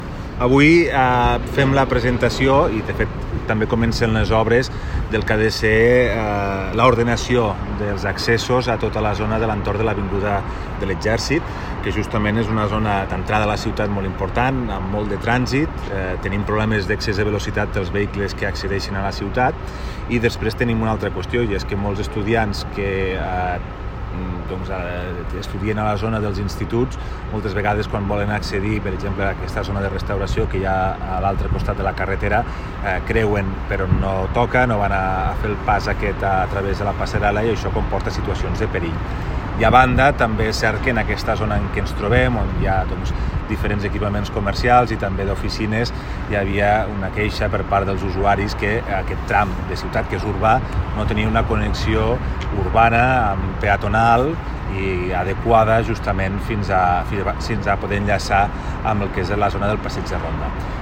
tall-de-veu-del-primer-tinent-dalcalde-toni-postius